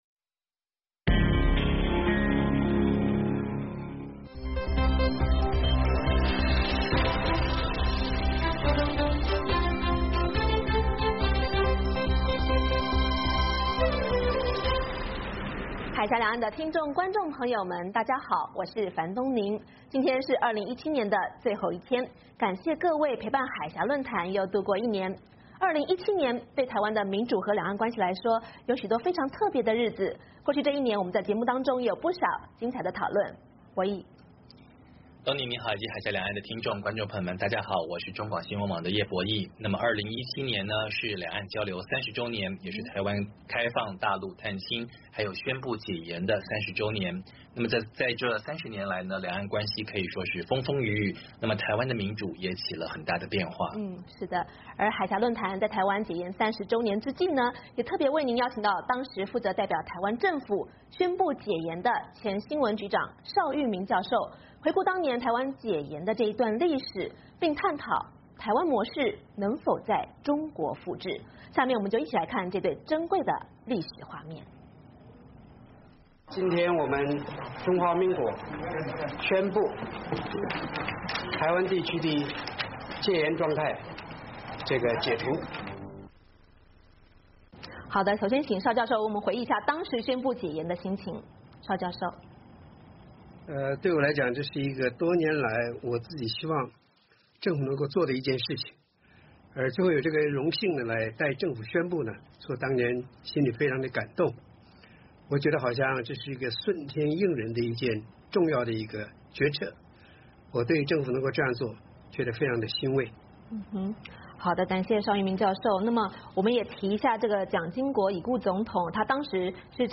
2017年是兩岸交流30週年，也是台灣開放大陸探親以及宣布解严30週年，30年来的两岸关系可以说是风风雨雨，台湾的民主也出现了很大的变化。海峡论谈在台湾解严30周年之际，特别为您邀请到当时负责代表政府宣布解严的前新闻局长邵玉铭教授，回顾当年台湾解严的这段历史，并探讨台湾模式是否能在中国复制。